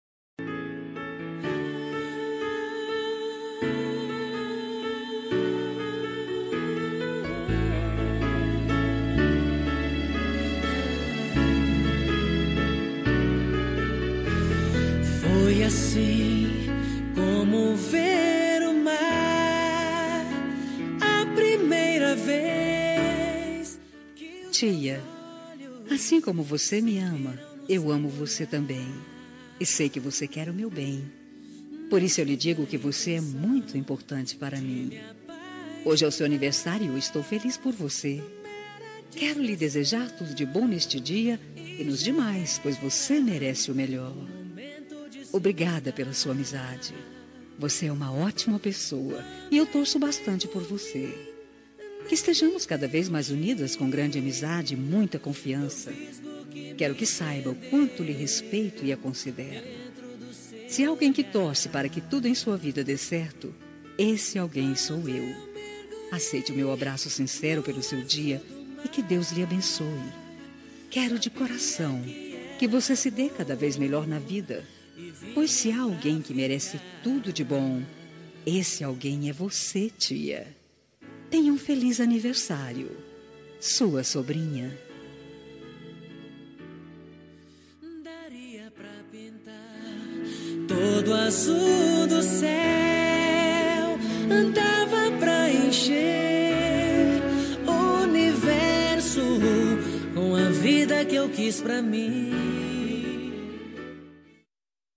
Telemensagem Aniversário de Tia – Voz Feminina – Cód: 4260